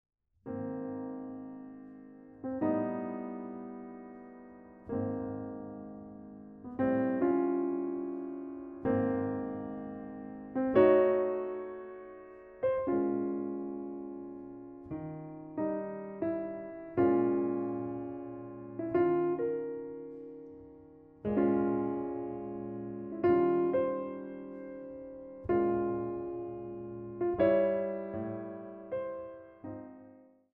様々な情景、抒情を見せるピアノ・ソロの世界が広がるアルバムとなっています。